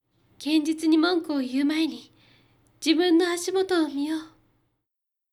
女性_「現実に嘘をついても、自分にはバレてるよ。」